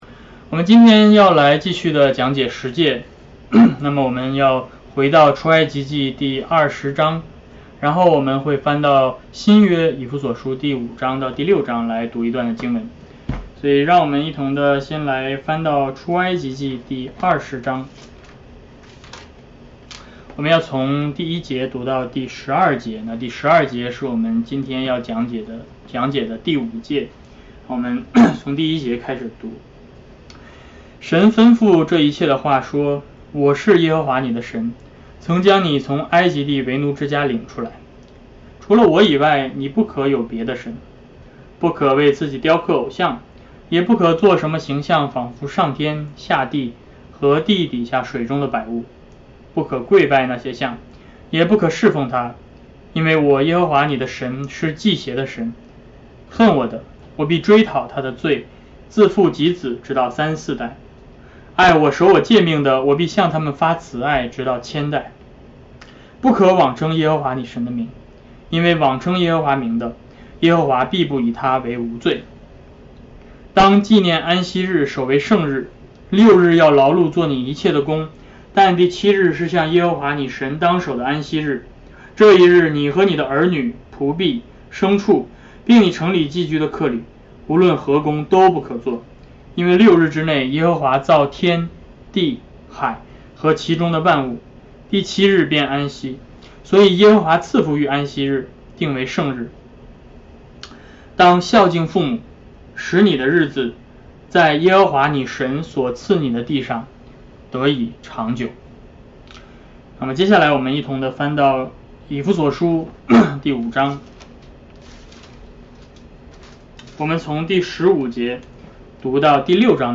Ephesians 5:15-6:9 Service Type: 主日讲道 Bible Text